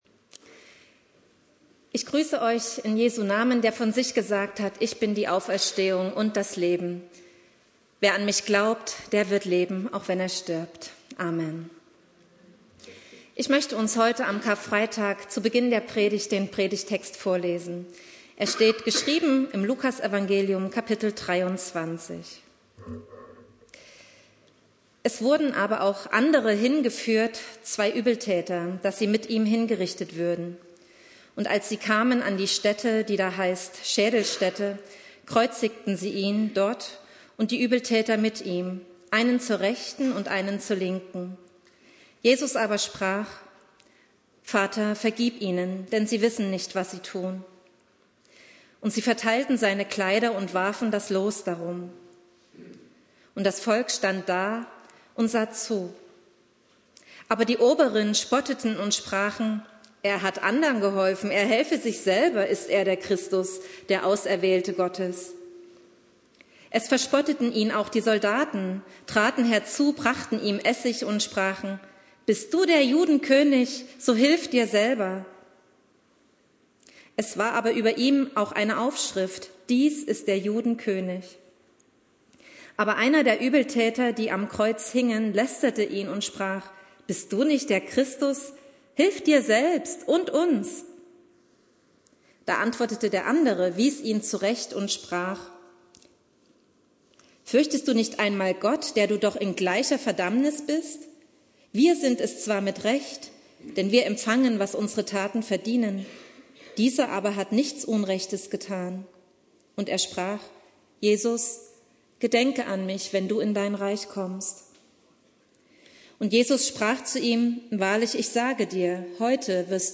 Predigt
Karfreitag